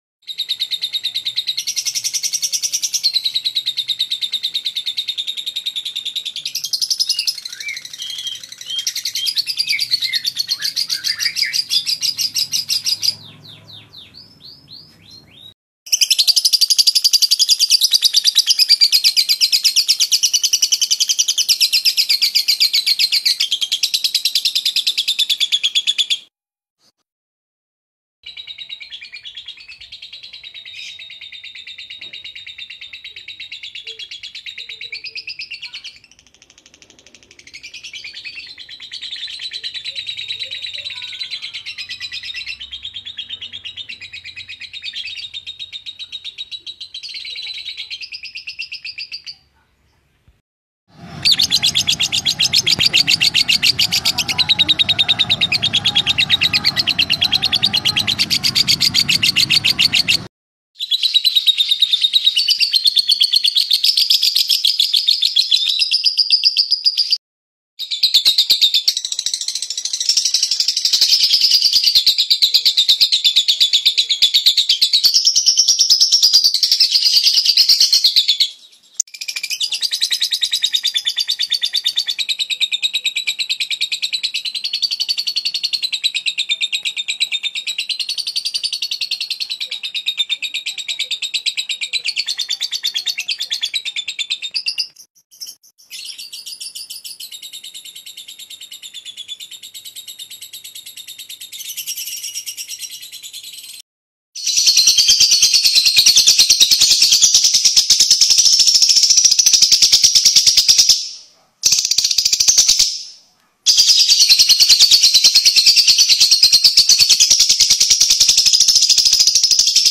جلوه های صوتی
دانلود صدای آواز زیبای مرغ عشق مست از ساعد نیوز با لینک مستقیم و کیفیت بالا